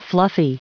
Prononciation du mot fluffy en anglais (fichier audio)
Prononciation du mot : fluffy